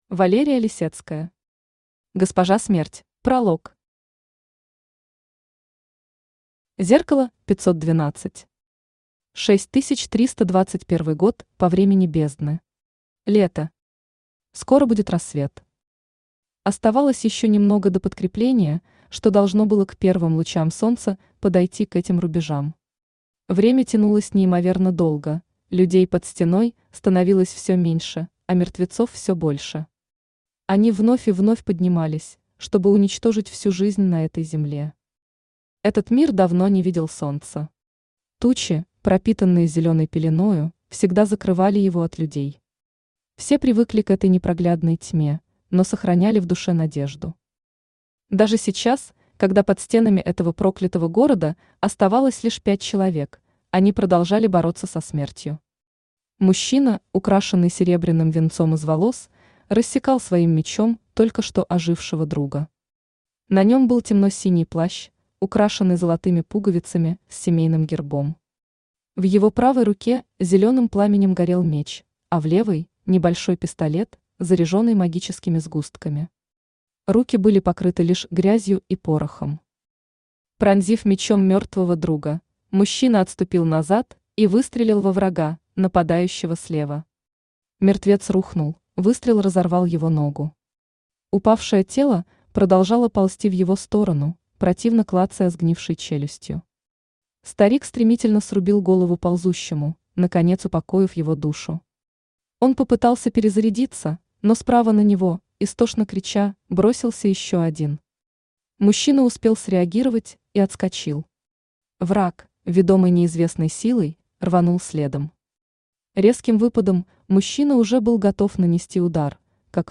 Аудиокнига Госпожа Смерть | Библиотека аудиокниг
Aудиокнига Госпожа Смерть Автор Валерия Лисецкая Читает аудиокнигу Авточтец ЛитРес.